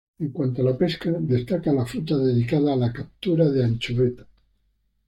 cap‧tu‧ra
/kabˈtuɾa/